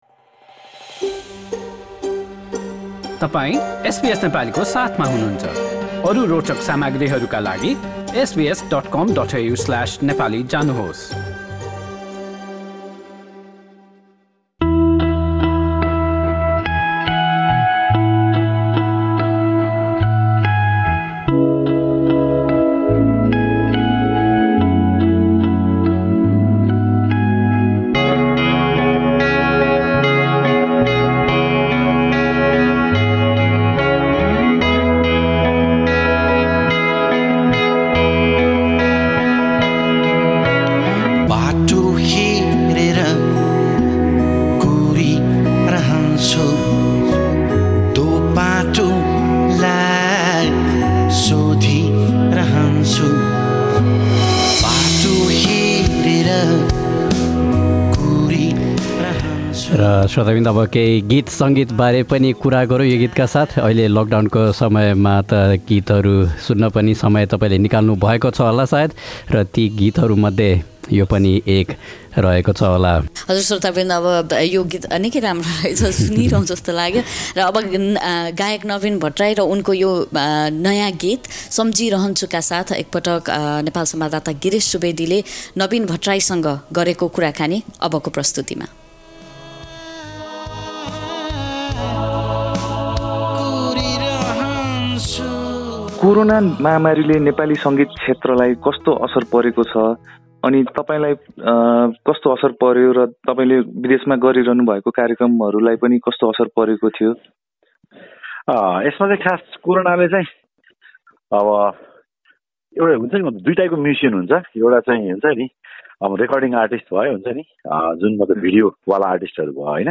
कुराकानी।